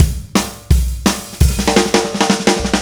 Indie Pop Beat 04 Fill B.wav